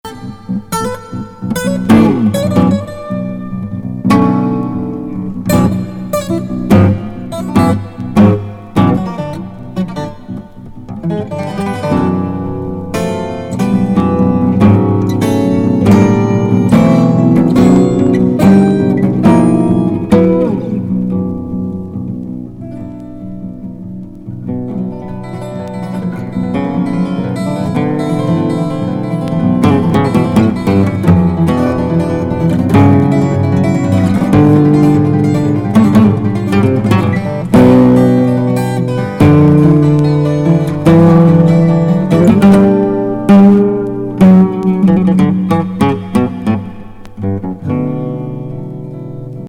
瞑想的神秘作ファースト・ソロ。